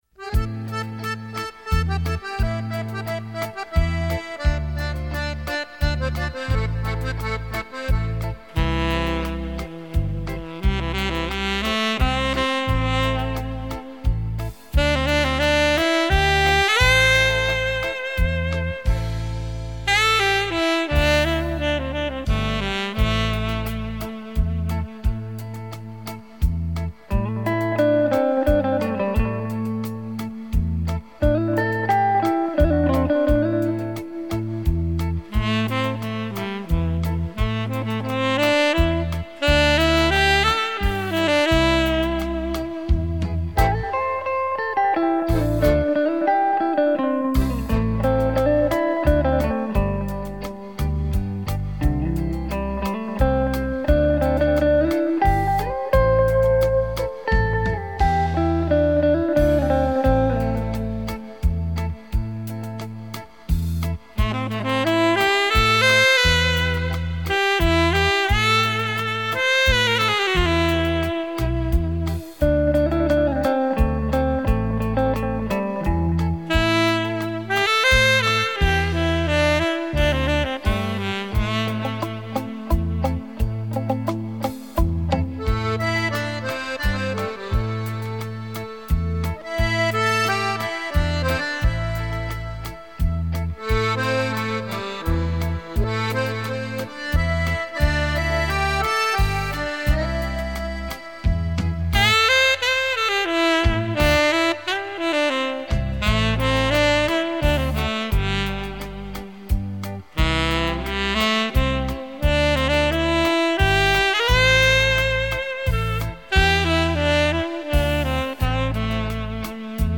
享受柔和优美的演奏 值得您细细品味收藏